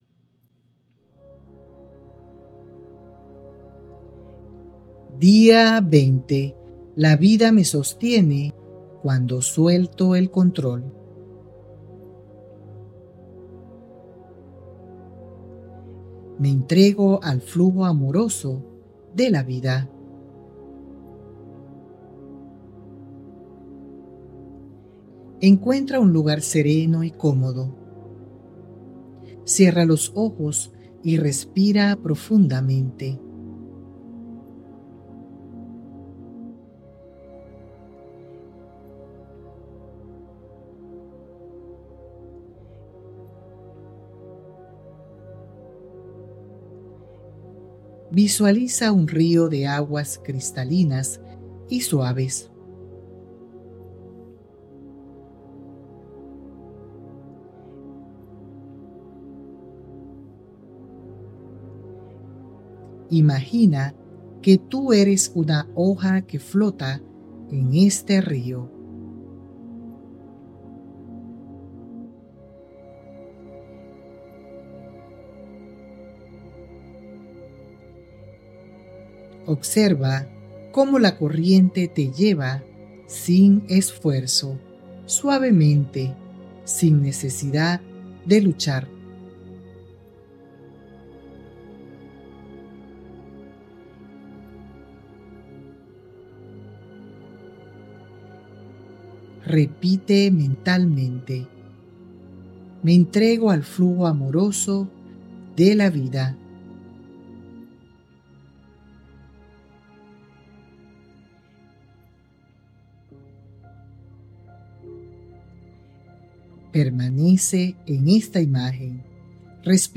🎧 Meditación Guiada: «Me entrego al flujo amoroso de la vida»